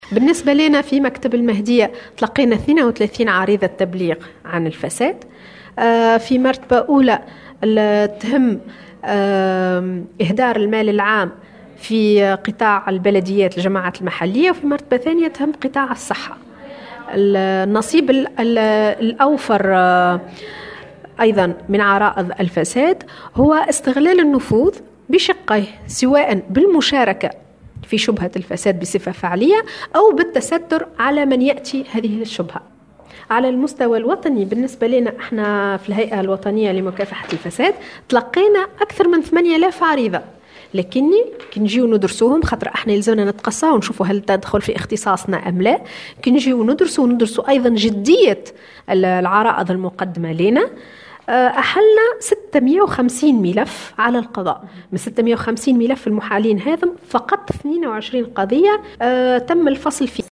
انعقدت صباح اليوم الاثنين، بمقرّ الهيئة الجهوية لمكافحة الفساد بالمهدية ندوة صحفية بمناسبة مرور سنة على دخول قانون التصريح بالمكاسب حيّز التنفيذ.